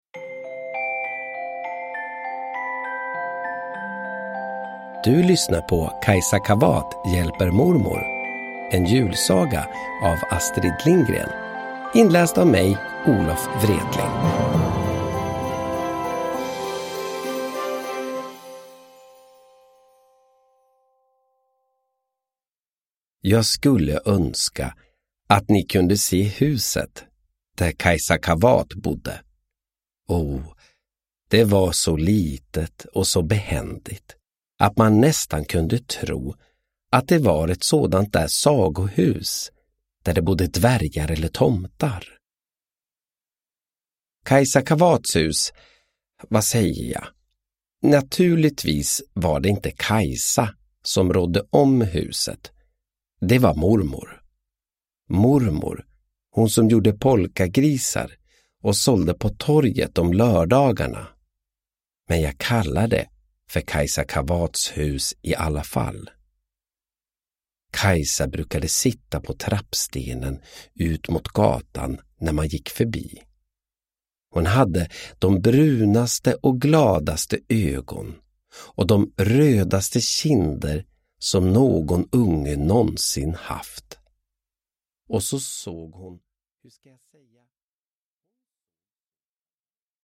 Vänta på julen och lyssna på Olof Wretling när han läser en mysig julsaga av Astrid Lindgren.
Uppläsare: Olof Wretling
• Ljudbok